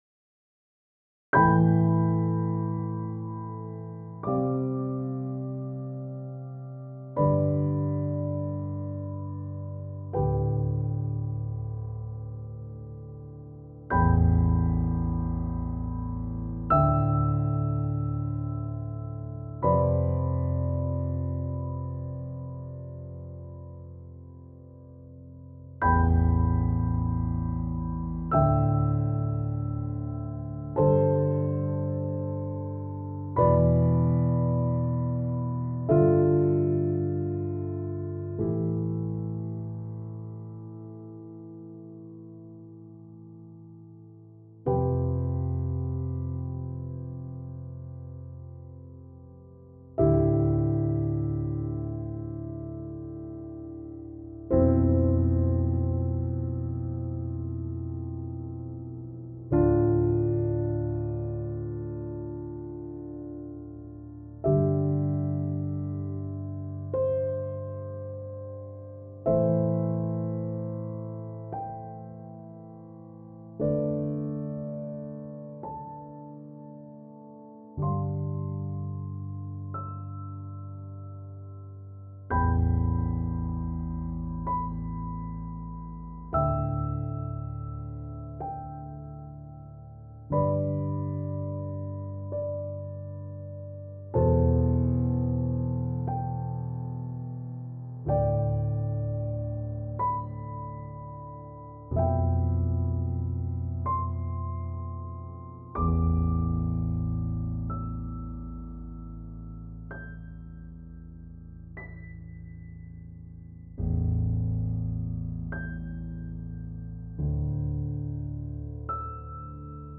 Deux compositeurs, un piano, un studio
improvisent
un joli album aux sonorités classiques et contemporaines